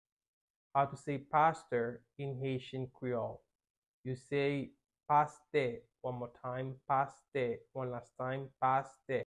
How to say "Pastor" in Haitian Creole - "Pastè" pronunciation by a native Haitian Teacher
“Pastè” Pronunciation in Haitian Creole by a native Haitian can be heard in the audio here or in the video below:
How-to-say-Pastor-in-Haitian-Creole-Paste-pronunciation-by-a-native-Haitian-Teacher.mp3